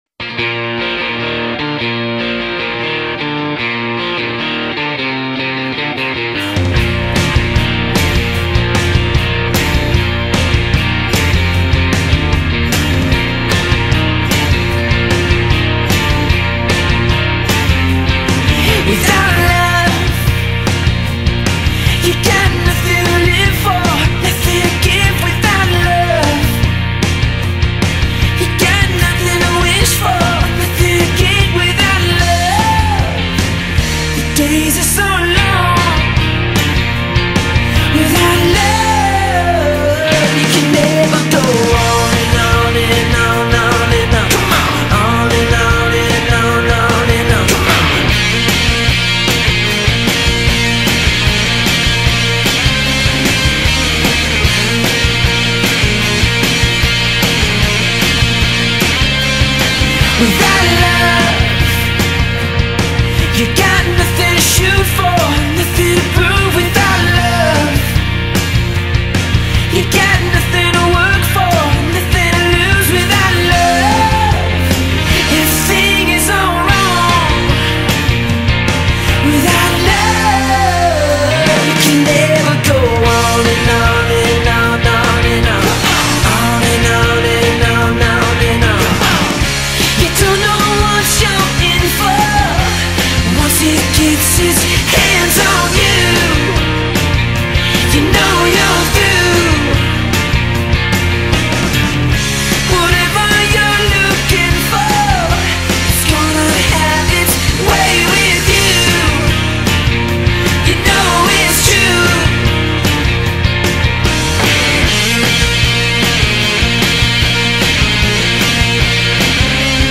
straight-up, guitar-driven poprock group
” which opens with a killer hook that just won’t let up